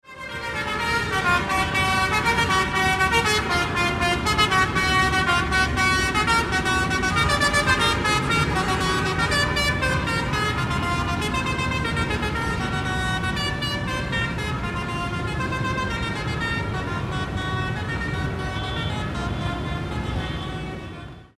Truck Horn Melody In Busy City Street Sound Effect
Description: Truck horn melody in busy city street sound effect. Loud truck horn melody sound effect echoing nonstop through a congested urban street.
Genres: Sound Effects
Truck-horn-melody-in-busy-city-street-sound-effect.mp3